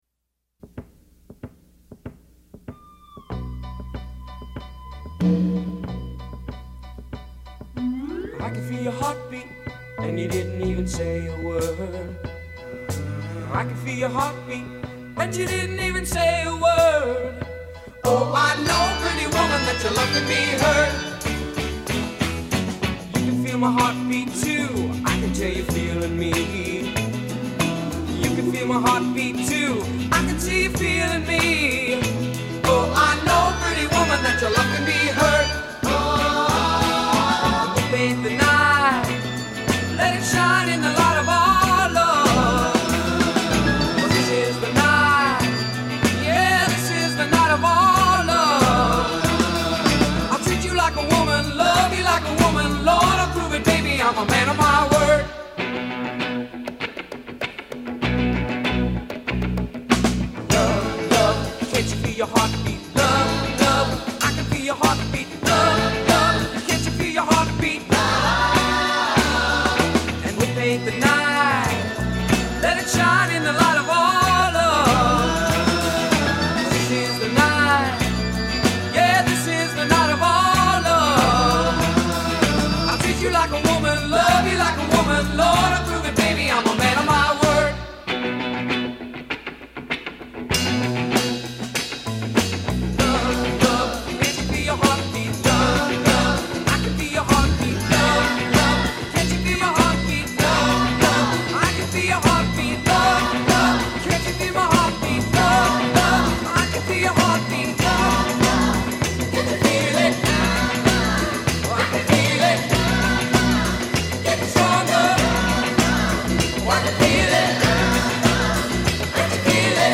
It’s of the era, for sure, but it’s sssssssolid.